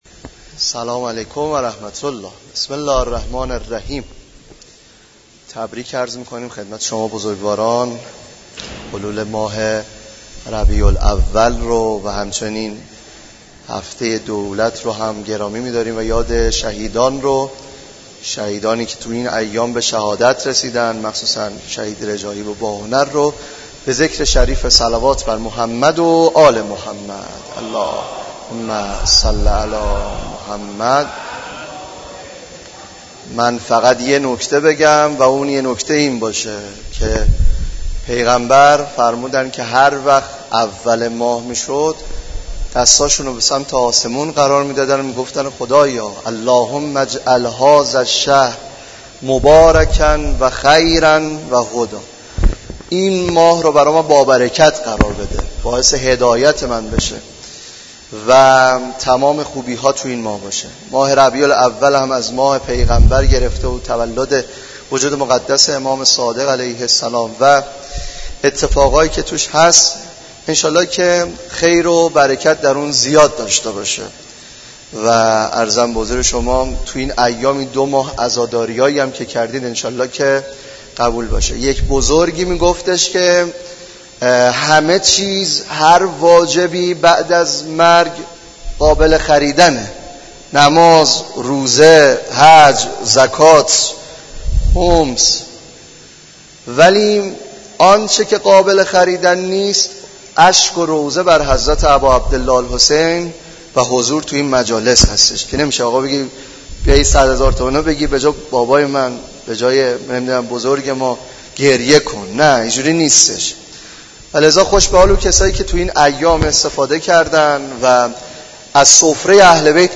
برگزاری سخنرانی
به مناسبت هفته دولت و حلول ماه ربیع الاول در مسجد دانشگاه کاشان